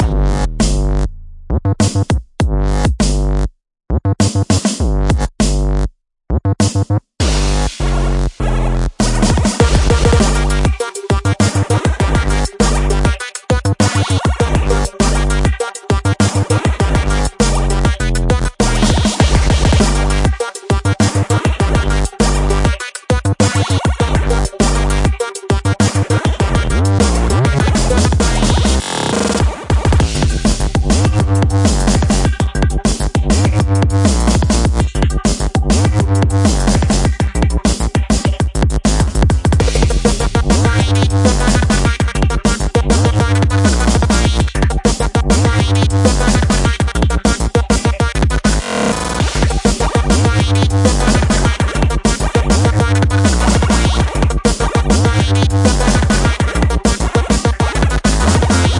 Chiptune DnBLoop（无缝循环）200bpm。半场到drumnbass过渡。